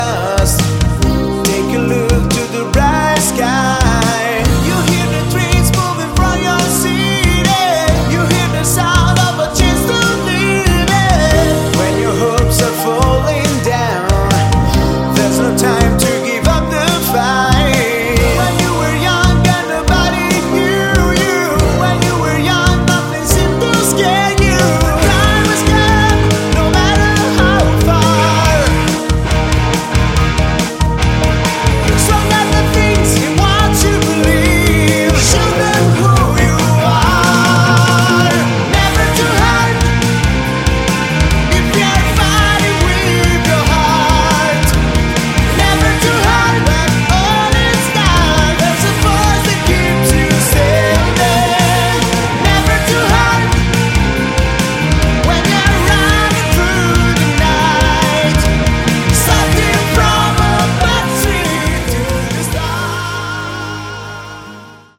Category: Hard Rock
vocals
guitars
drums
bass
keyboards